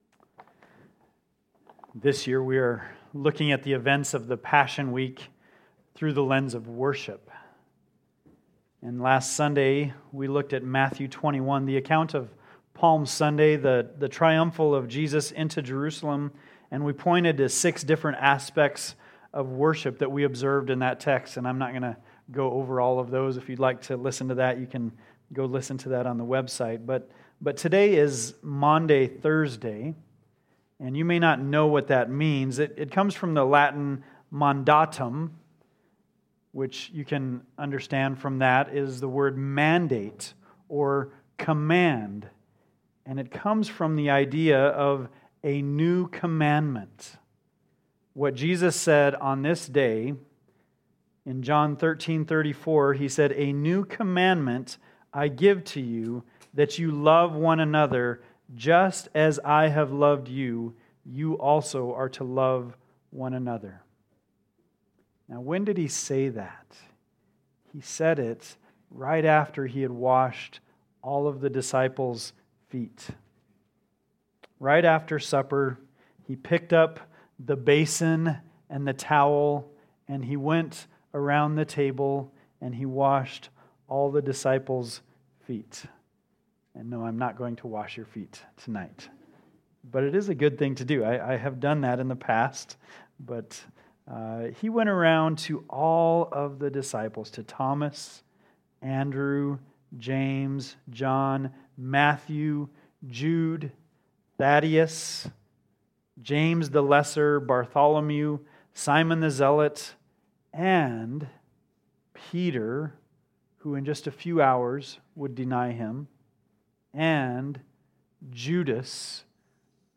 Maunday-Thursday-Sermon.mp3